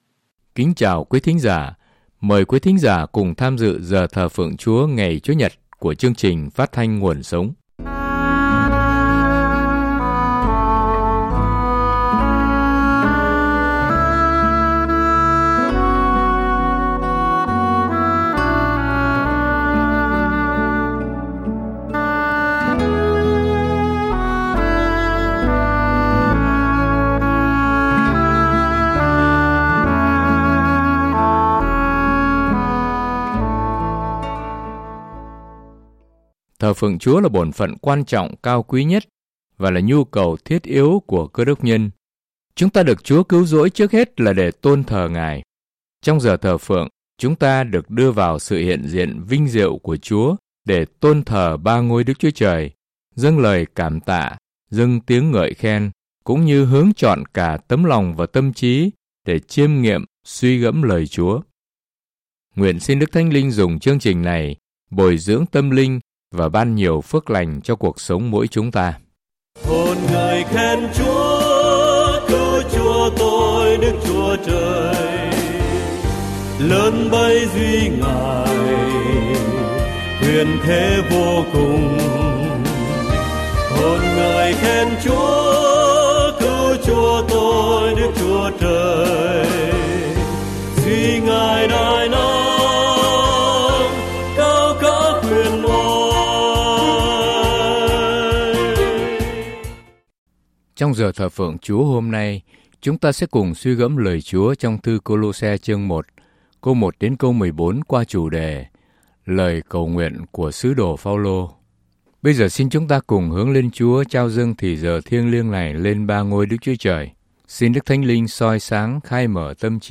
Thờ Phượng Giảng Luận